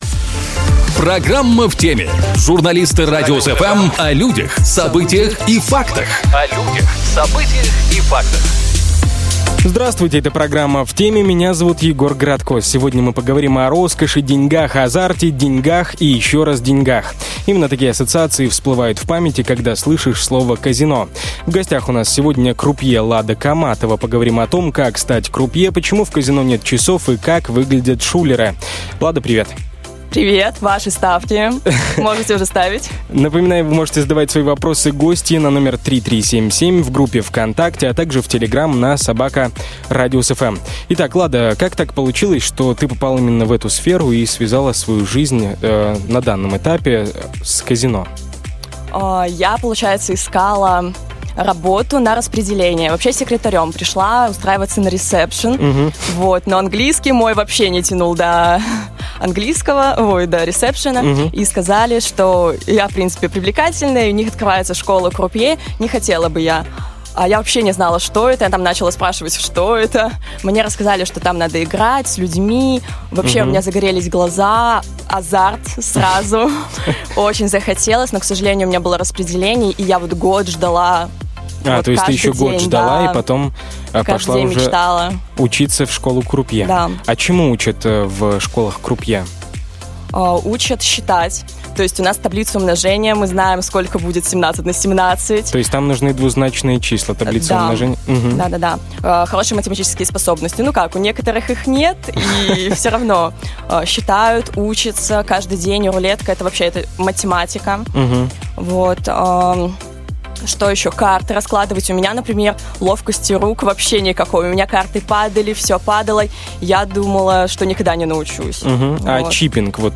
В гостях у нас сегодня крупье